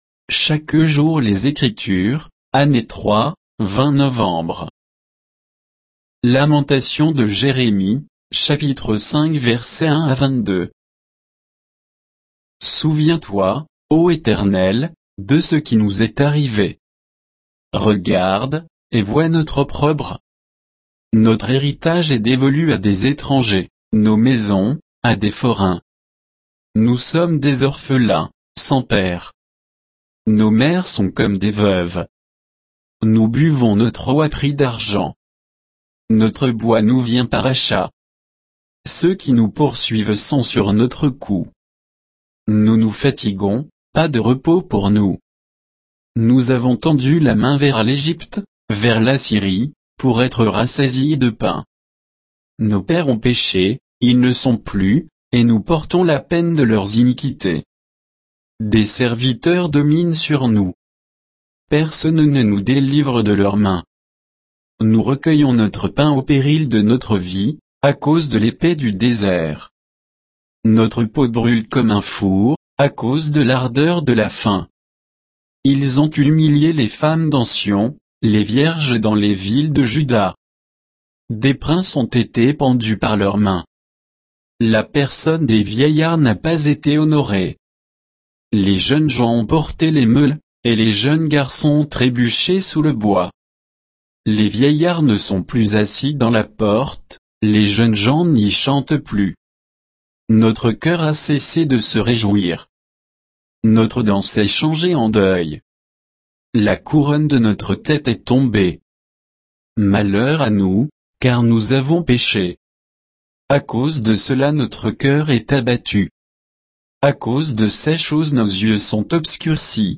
Méditation quoditienne de Chaque jour les Écritures sur Lamentations de Jérémie 5